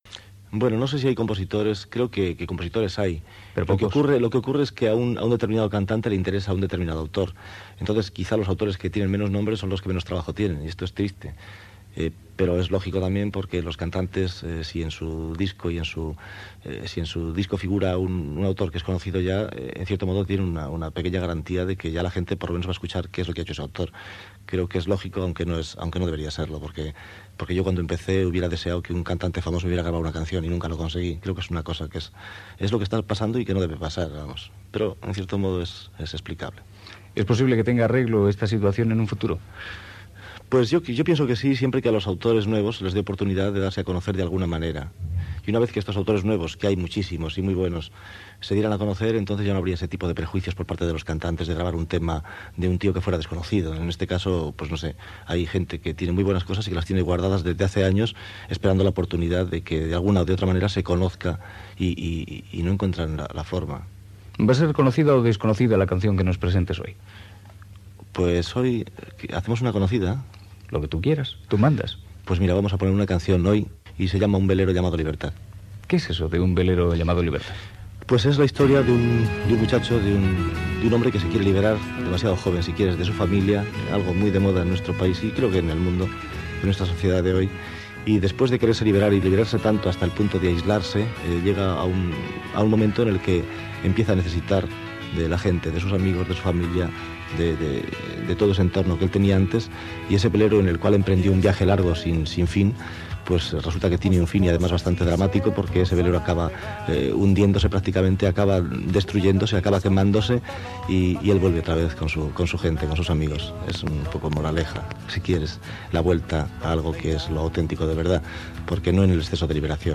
Entrevista al cantant José Luis Perales que presenta el tema "Un velero llamado libertad"
Entreteniment